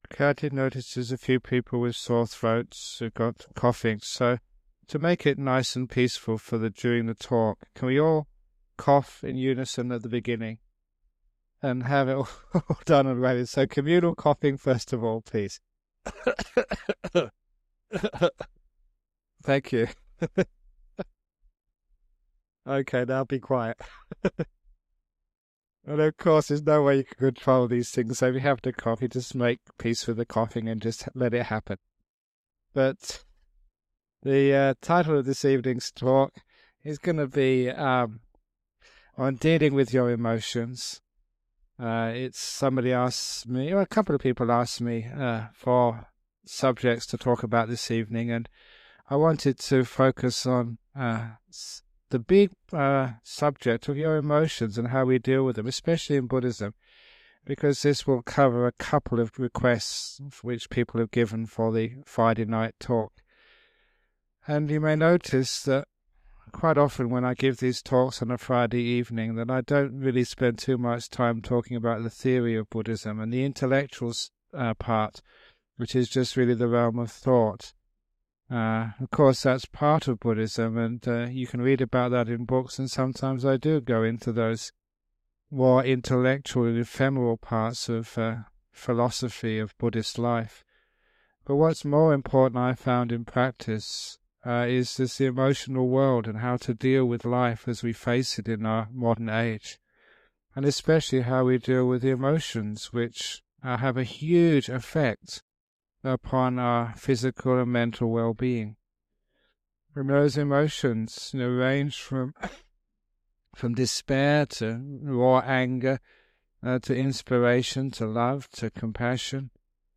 This talk is about dealing with emotions from a Buddhist perspective. Ajahn Brahm points out that often people talk about negative emotions such as grief, anger, and fear, but positive emotions like inspiration, love, and compassion are also important.